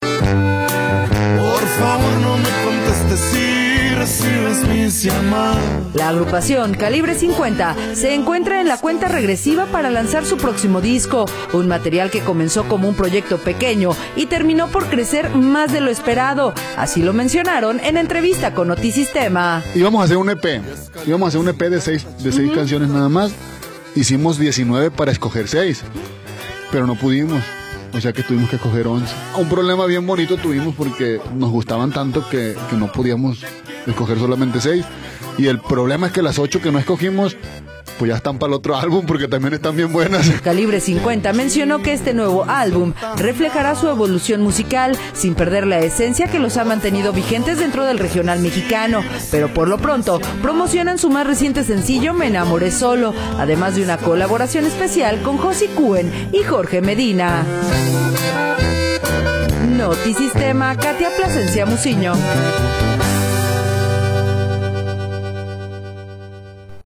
audio La agrupación Calibre 50 se encuentra en la cuenta regresiva para lanzar su próximo disco, un material que comenzó como un proyecto pequeño y terminó por crecer más de lo esperado, así lo mencionaron en entrevista con Notisistema.